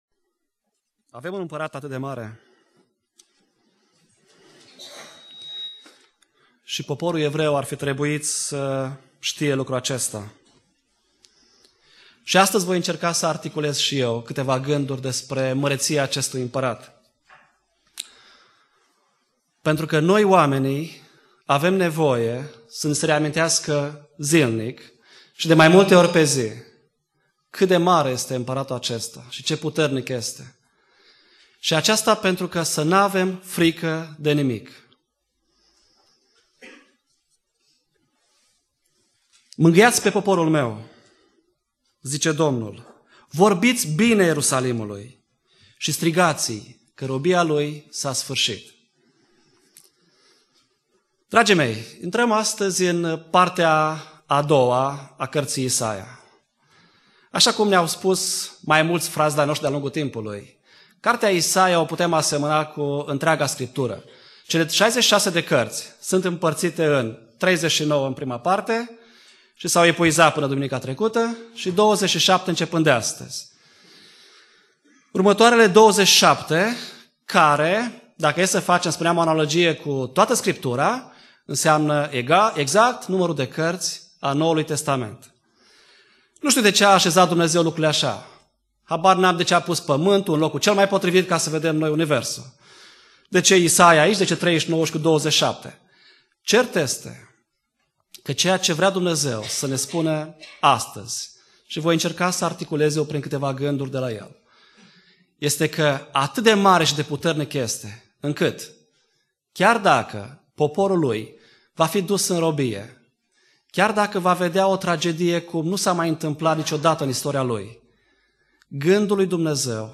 Predica Exegeza - Isaia 40-42